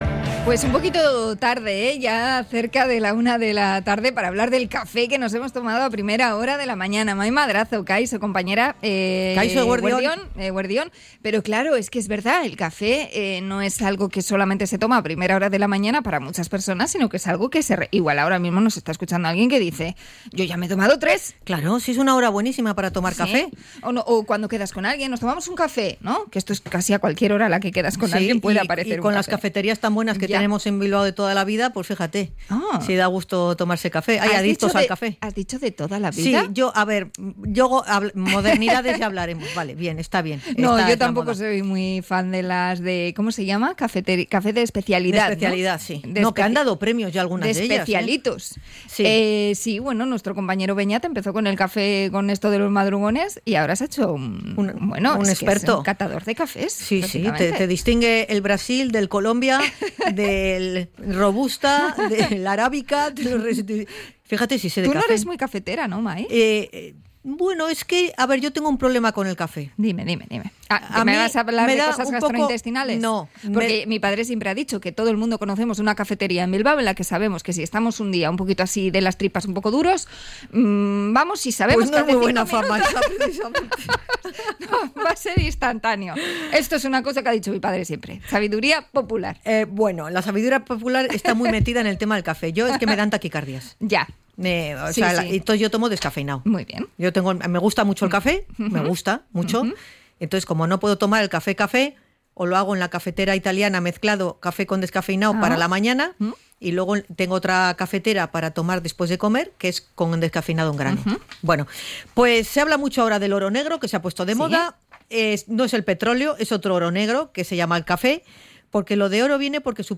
ENTREVISTA-CAFE.mp3